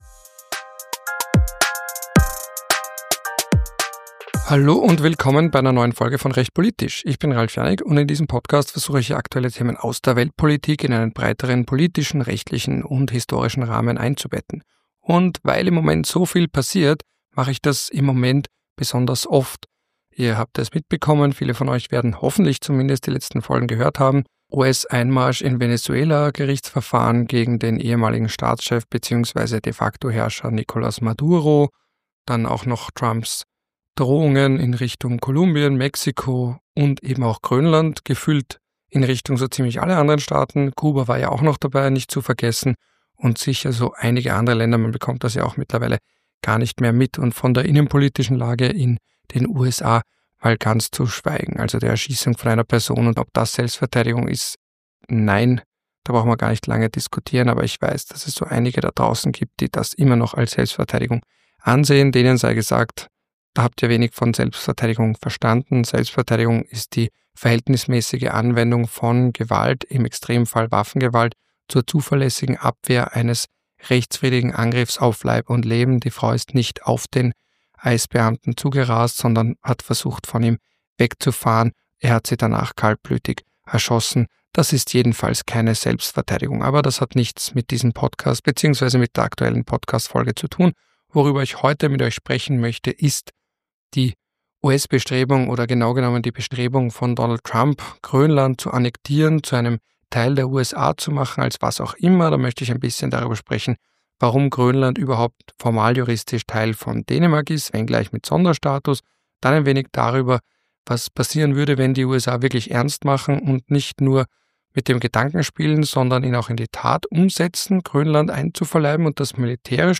Solltet ihr ein seltsames Knacken hören, bitte die Folge neu laden!